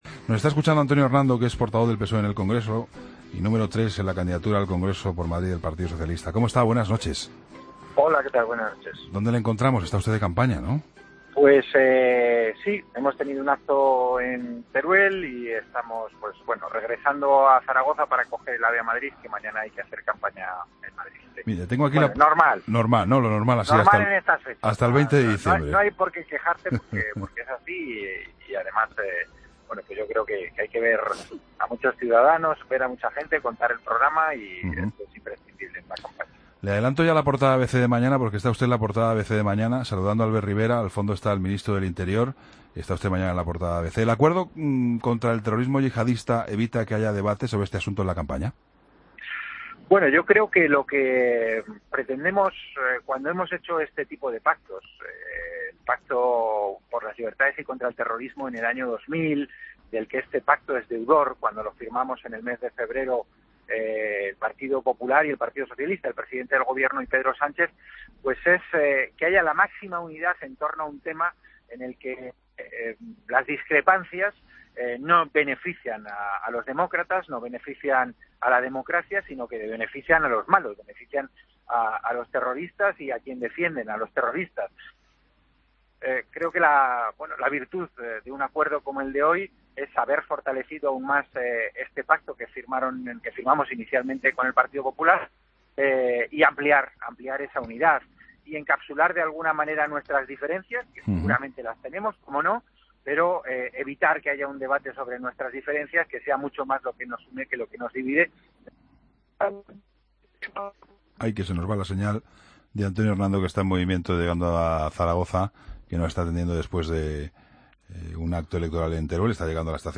AUDIO: Entrevista a Antonio Hernando, Portavoz del PSOE en el Congreso, sobre el pacto antiyihadista